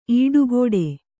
♪ īḍu gōḍe